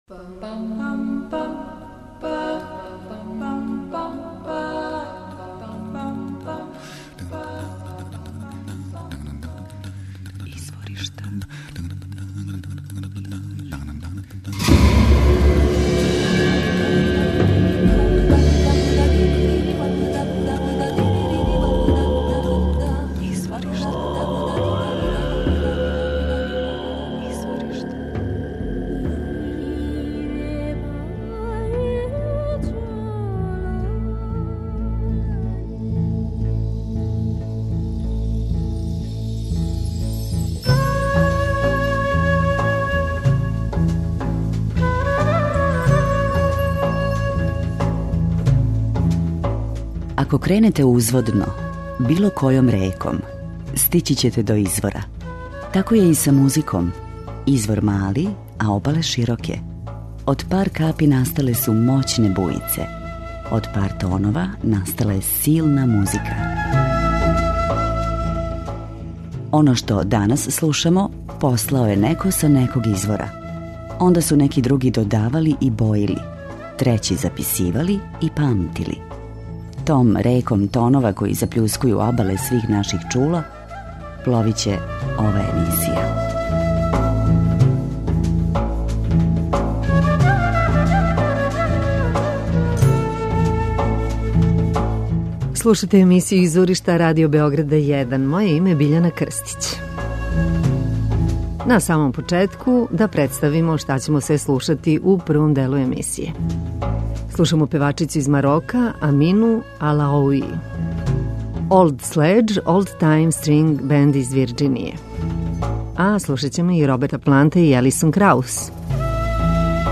old time string band